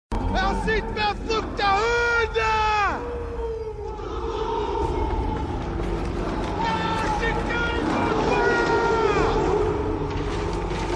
Gladiator Arena Echo Bouton sonore